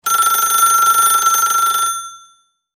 Здесь вы найдете характерные шумы вращающегося диска, гудки ожидания и другие аутентичные эффекты.
Звон ретро телефона